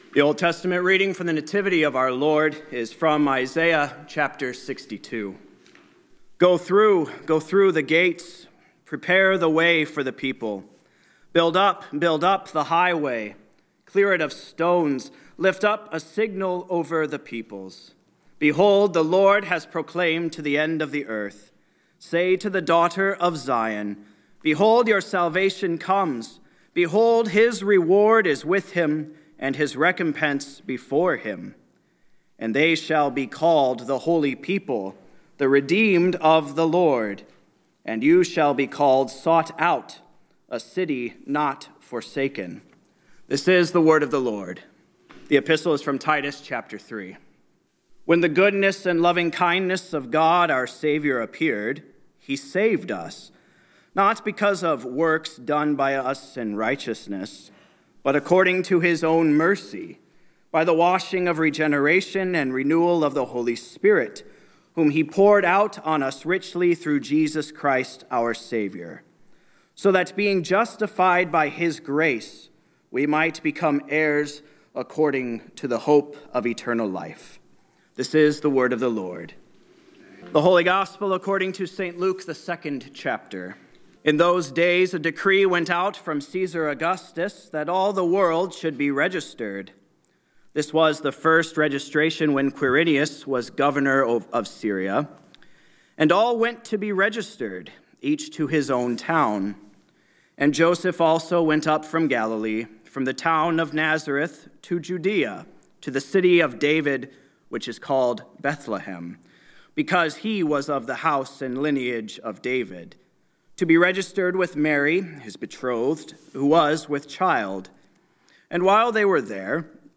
Word & Sermon Weekly – Christmas Day – 12/25/2022